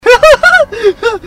Laugh 7